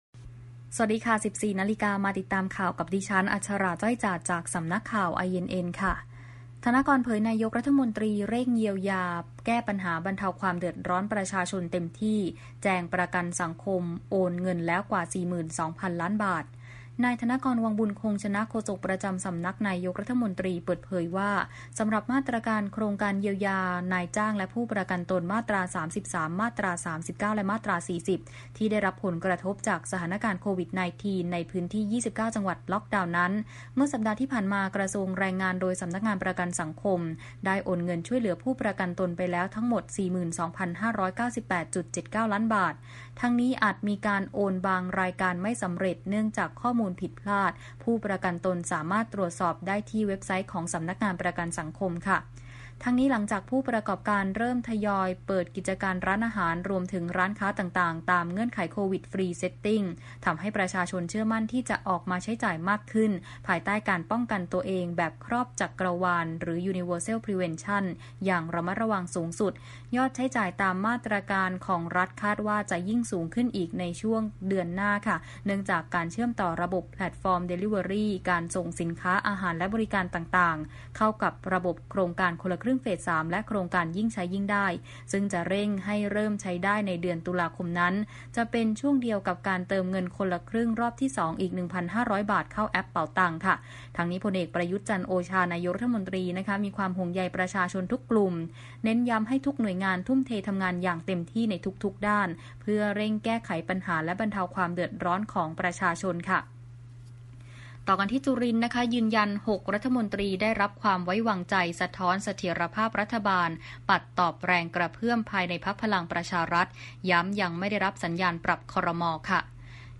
ข่าวต้นชั่วโมง 14.00 น.